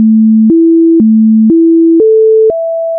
The resource sineticking3secs.wav, was made using Audacity. Format: 16-bit, 44100 fps, stereo, little-endian. It was made by appending 500 millisecond length sine tones using the Generate tool, at 220, 330, 220, 330, 440, 660 Hz.
sineticking3secs.wav